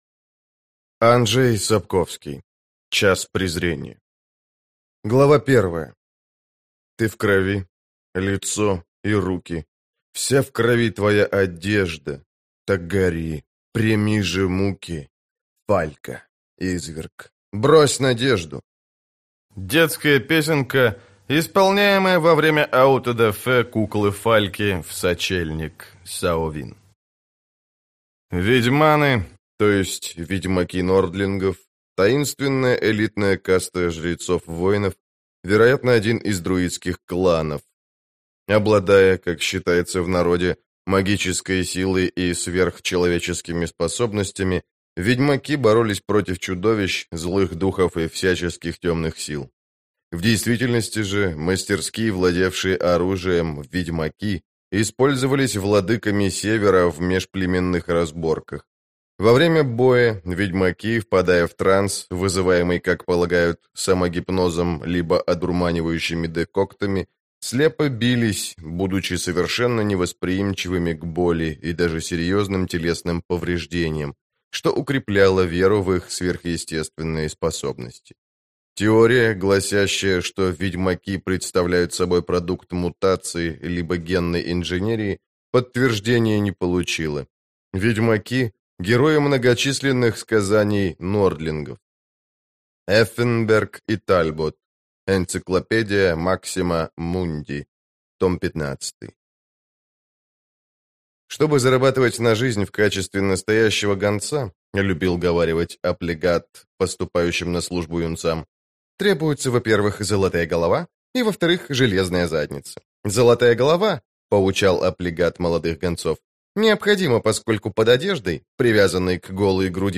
Аудиокнига Час Презрения | Библиотека аудиокниг